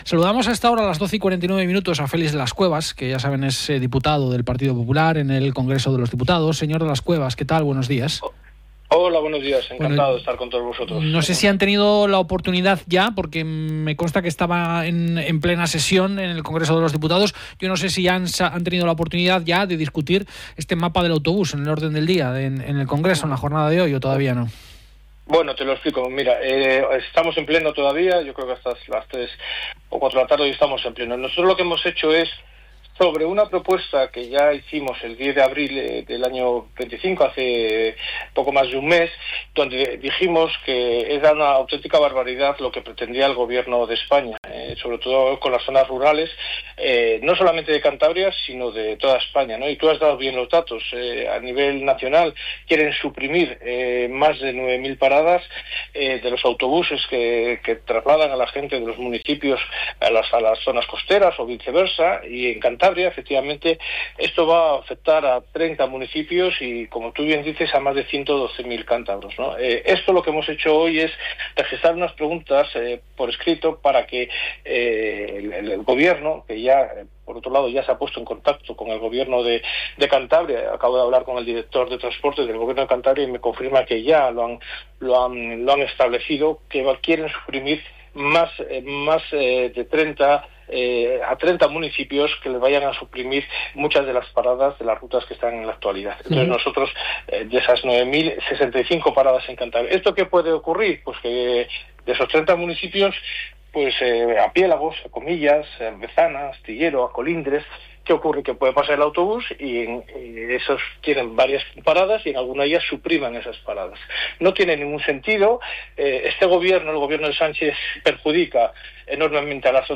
-Entrevista-Más de uno Cantabria. Onda Cero